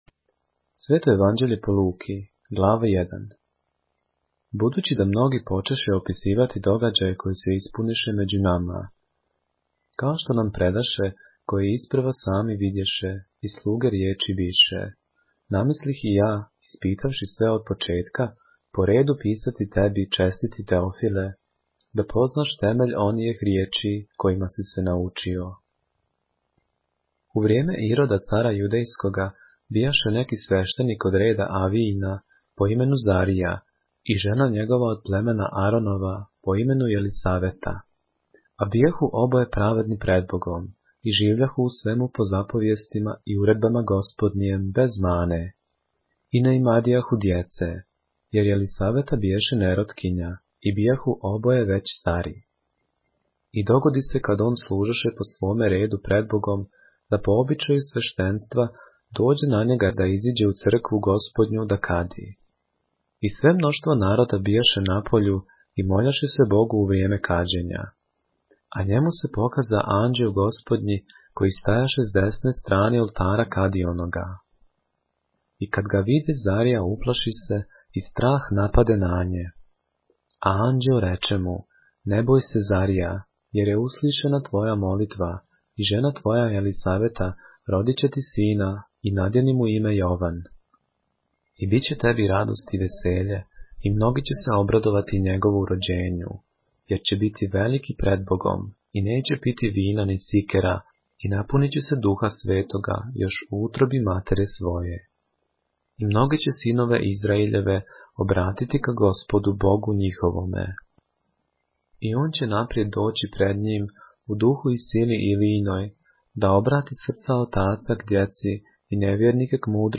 поглавље српске Библије - са аудио нарације - Luke, chapter 1 of the Holy Bible in the Serbian language